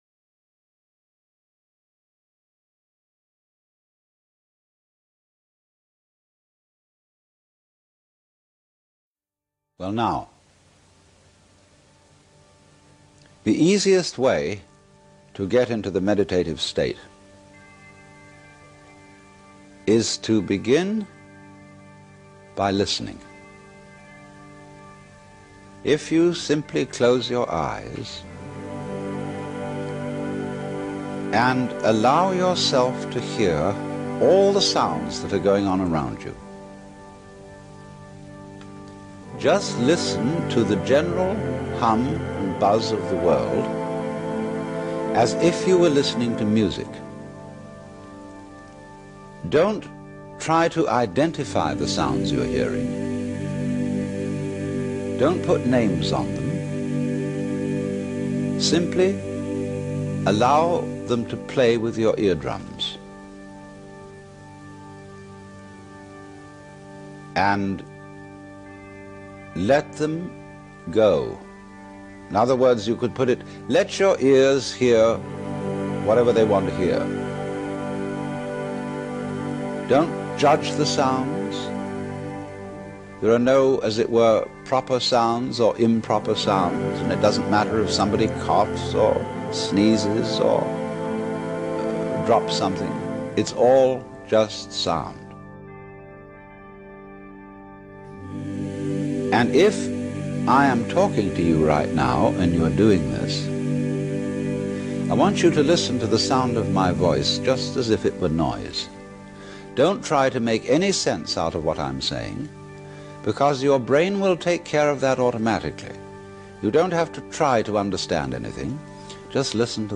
Alan_Watts_-_Guided_Meditation_Awakening_The_Mind_.ogg